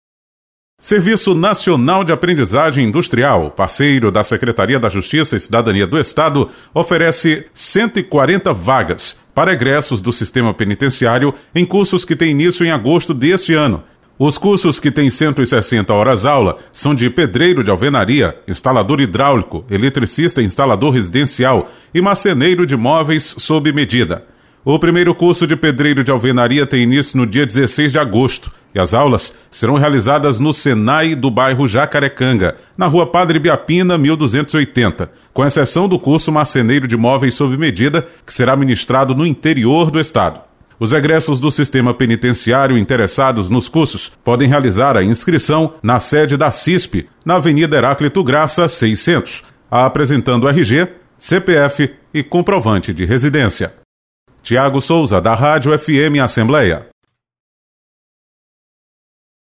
Convênio entre Senai e Sejus oferece cursos com 140 vagas. Repórter